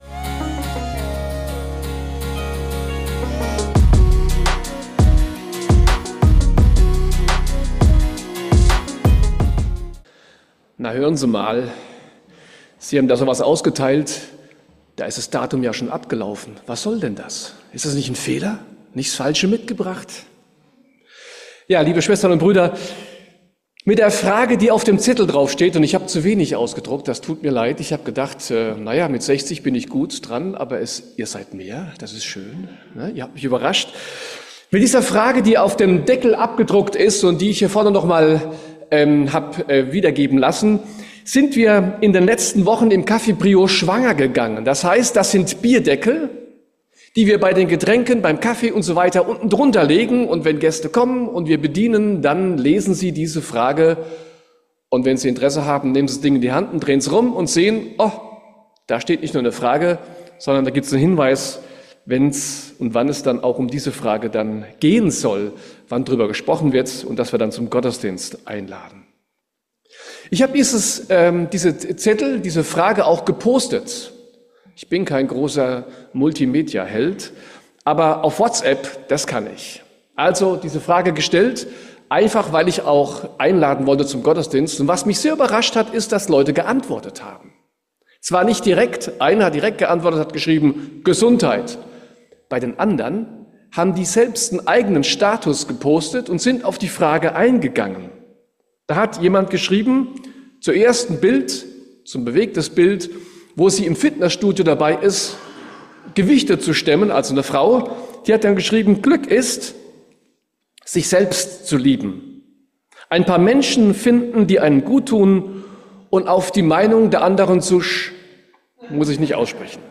Wie du die Frage beantworten kannst, hörst du in der Predigt. Im Café Prio der Stadtmission gibt es Bierdeckel, auf denen diese Frage steht.
In der Predigt wird ein Lied gesungen.